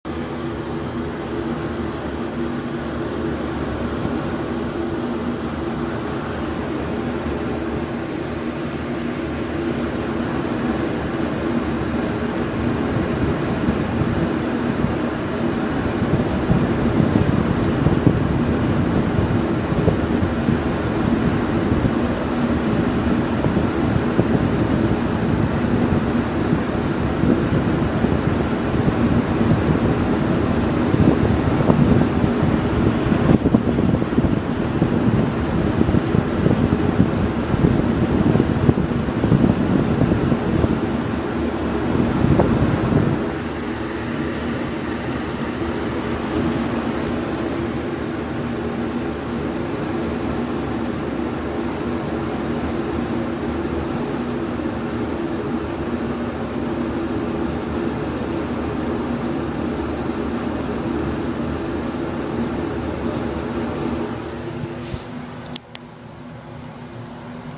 2/17/2012 at 9:30 in the morning A noisy air vent on the 13th floor bathroom in Estabrook Tower.
air-vent.mp3